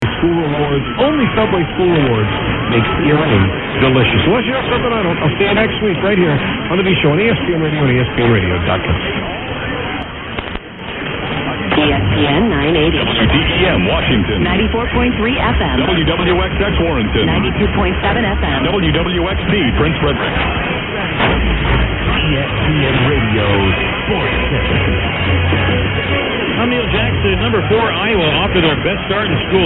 091108_0900_980_wtem_exc_multiple_call.mp3